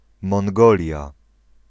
Ääntäminen
Ääntäminen US UK : IPA : /mɒŋˈɡəʊliə/ US : IPA : /mɑːŋˈɡoʊliə/ Lyhenteet ja supistumat (laki) Mong.